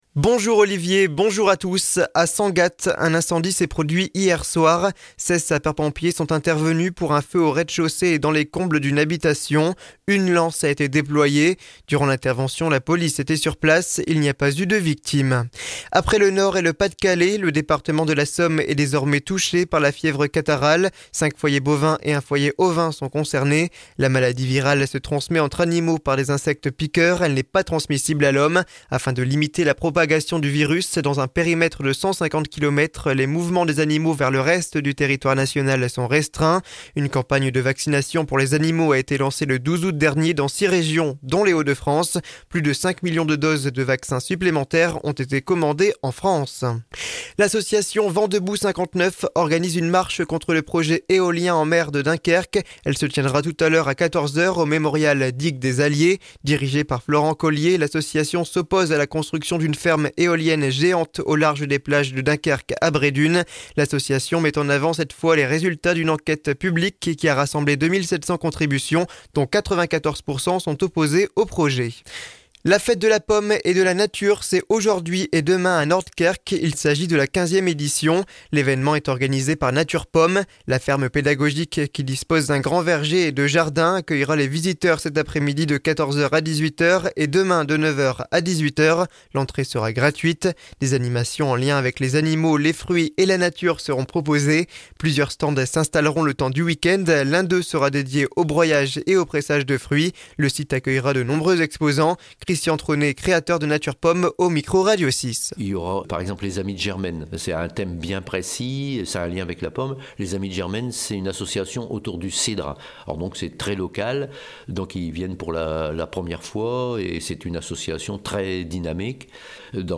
(journal de 9h)